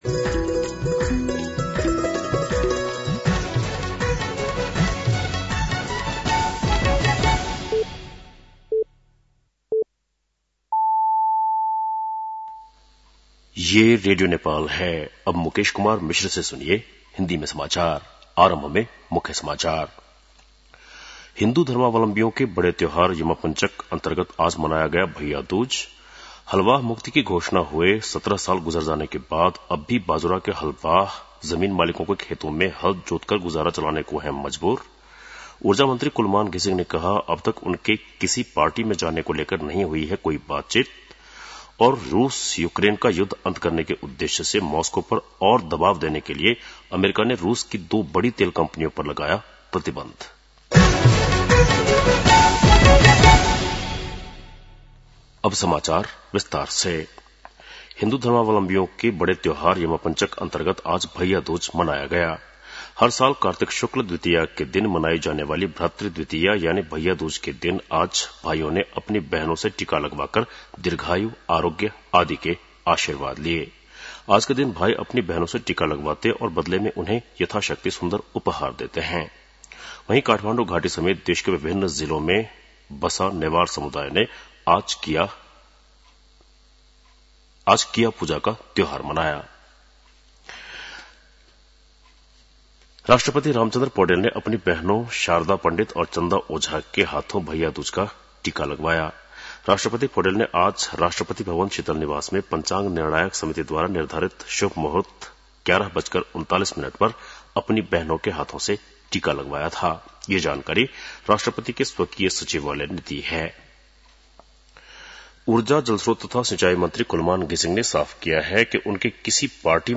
बेलुकी १० बजेको हिन्दी समाचार : ६ कार्तिक , २०८२
10-PM-Hindi-NEWS-7-6.mp3